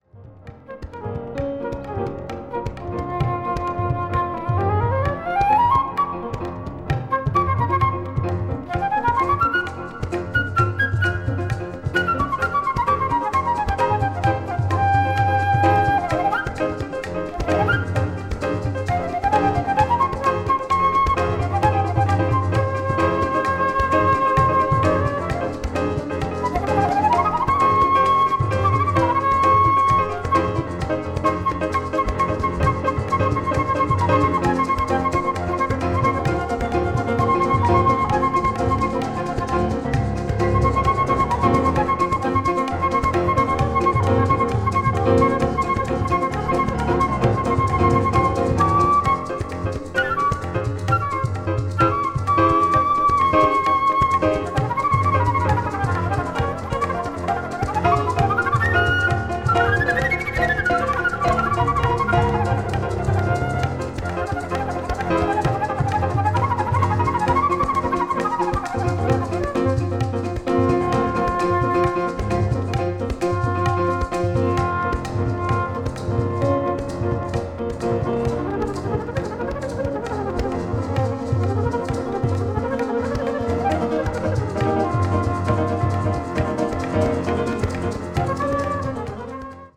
arab   contemporary jazz   ethnic jazz   world music